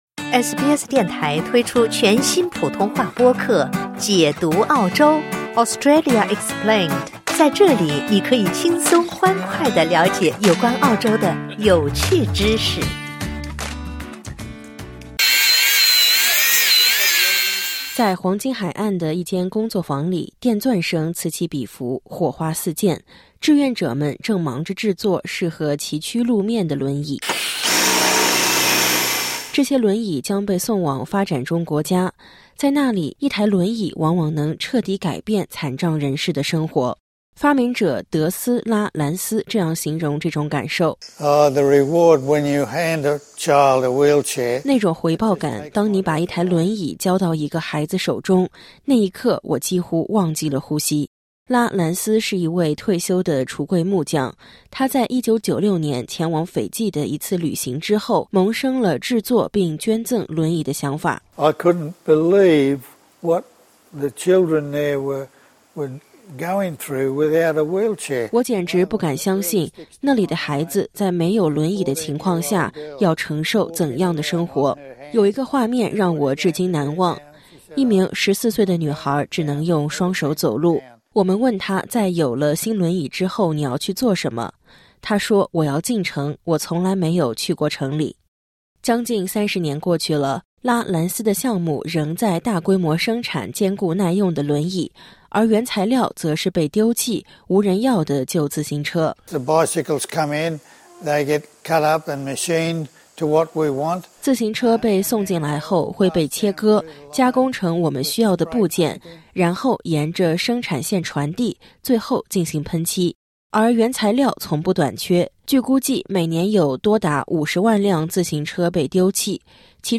正是出于这样的信念，澳大利亚一群志愿者将废弃的旧自行车改造成轮椅，并捐赠给世界各地的慈善机构。点击音频，收听完整报道。
在黄金海岸的一间工作坊里，电钻声此起彼伏，火花四溅，志愿者们正忙着制作适合崎岖路面的轮椅。